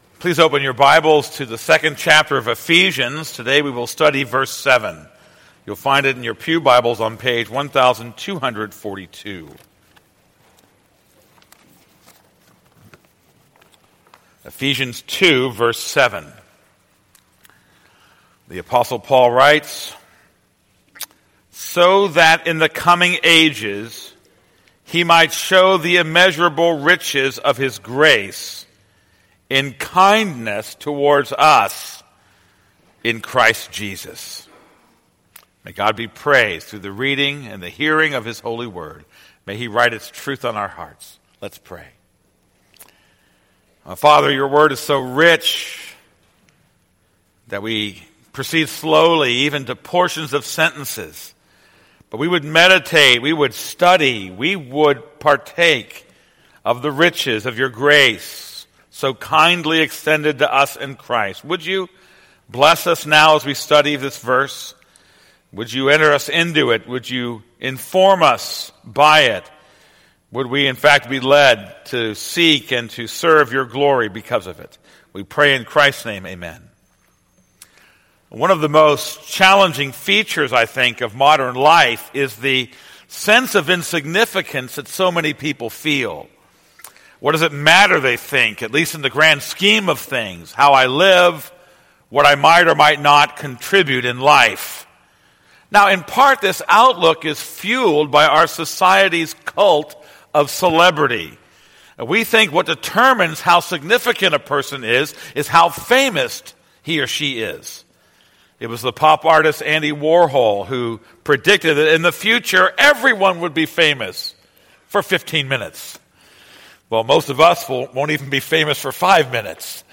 This is a sermon on Ephesians 2:7.